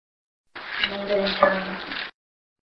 Harney Mansion EVP
The raw but slightly amplified EVP